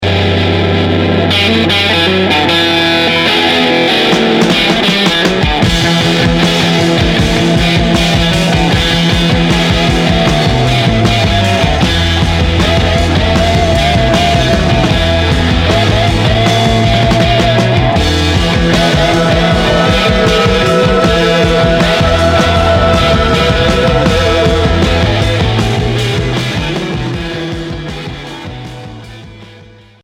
Heavy prog psyché Unique 45t retour à l'accueil